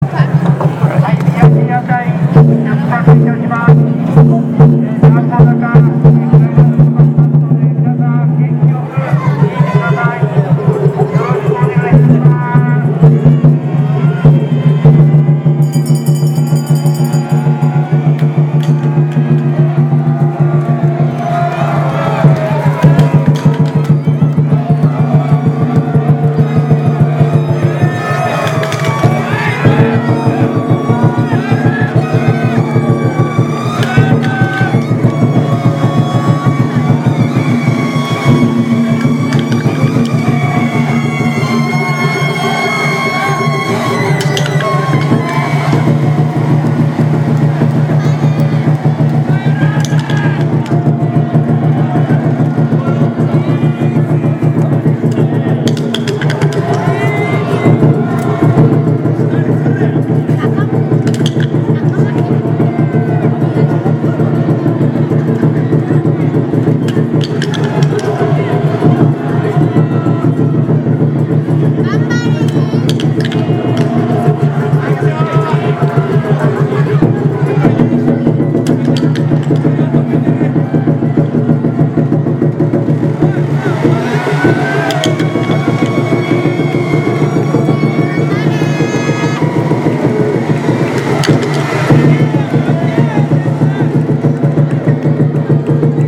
・　秩父夜祭の熱気に溢れる音をアップしました。
◎　屋台を引く掛け声（２）
坂を上った広場には多くの屋台と引き手が大群衆になってひしめき合っていました。